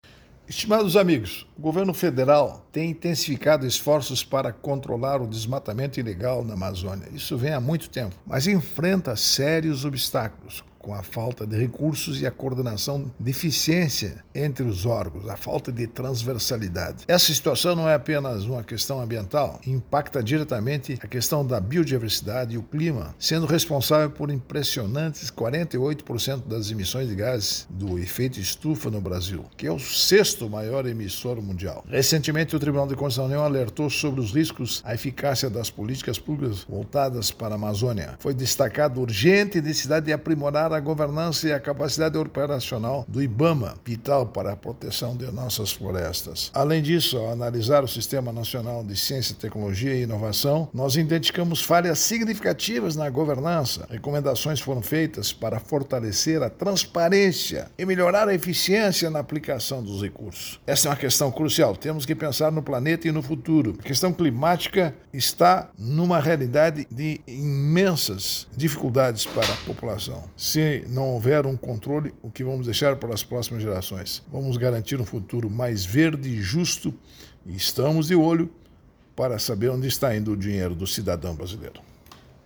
Comentário de Augusto Nardes, ministro TCU.